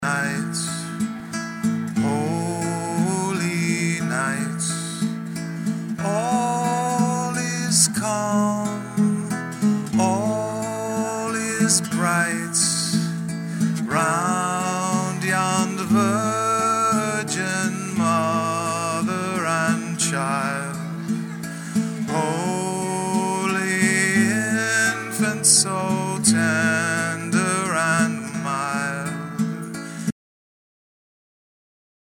recorded IN CONCERT